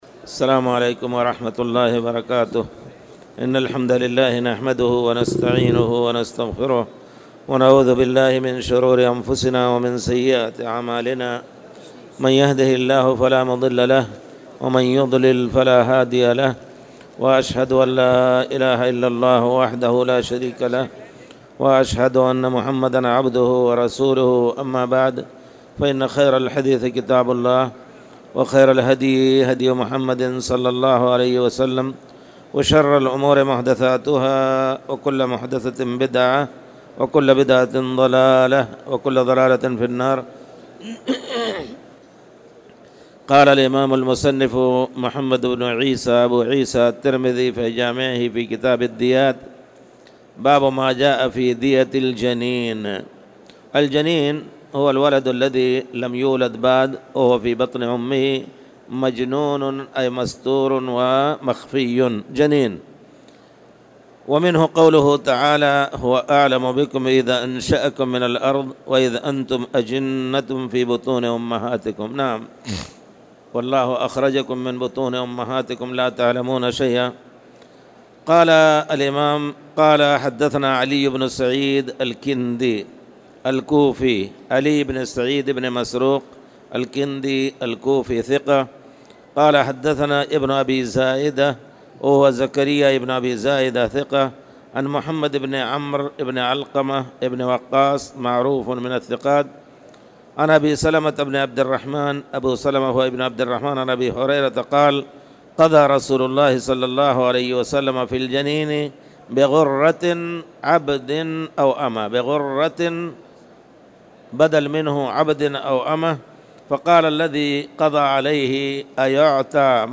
تاريخ النشر ٣ جمادى الآخرة ١٤٣٨ هـ المكان: المسجد الحرام الشيخ